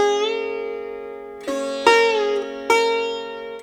SITAR LINE50.wav